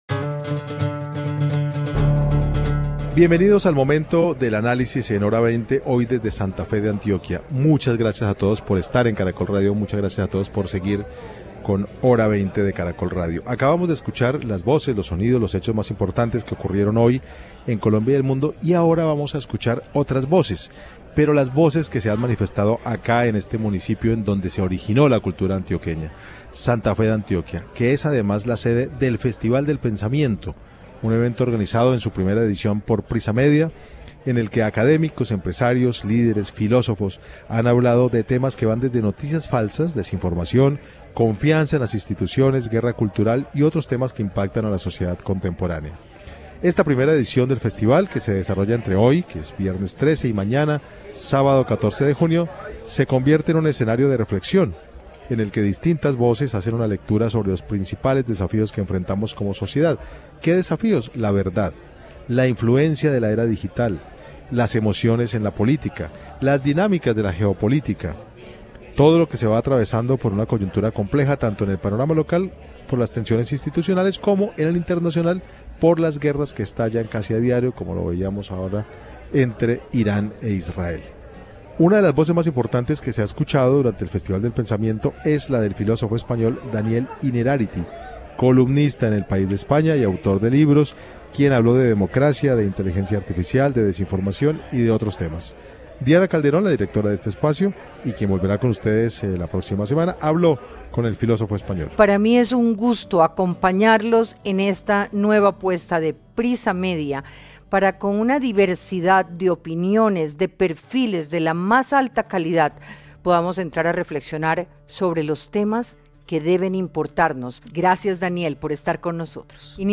Hora 20 desde el Festival del Pensamiento